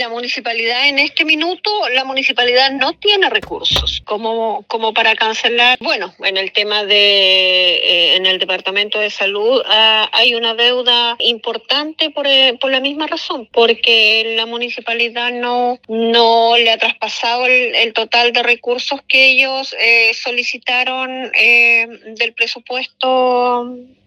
En ese contexto, la concejala Jessica Oyarzo, indicó que esto se debe a que la casa edilicia no tiene recursos y presenta grandes deudas.